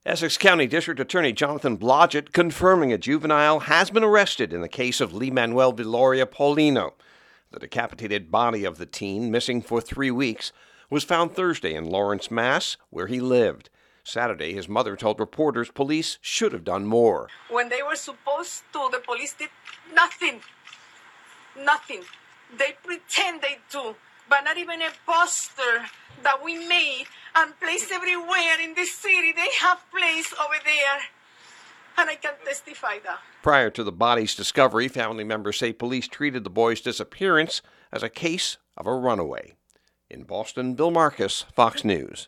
(BOSTON) DEC 3 – THE DISTRICT ATTORNEY IN ESSEX COUNTY MASSACHUSETTS ANNOUNCING AN ARREST IN THE CASE OF THE HIGH SCHOOL SOPHOMORE FOUND DECAPITATED ALONG THE MERRIMACK RIVER.